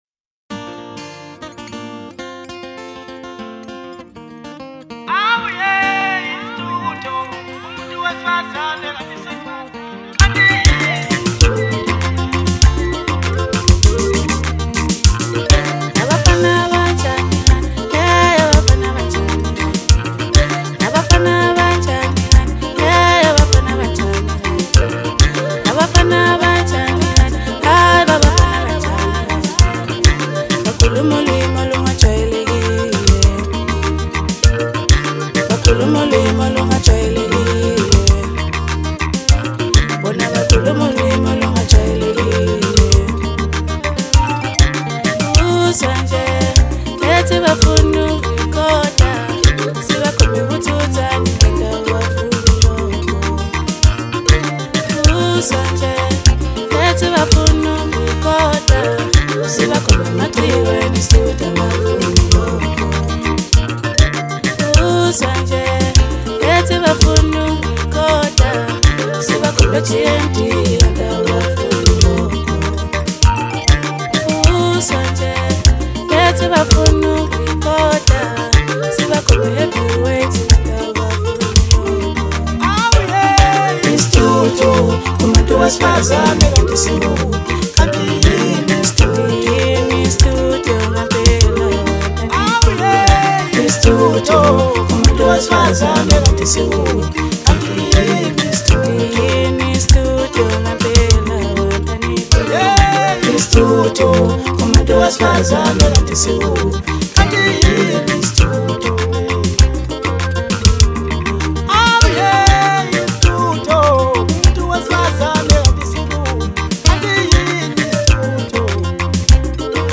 Home » Maskandi » Hip Hop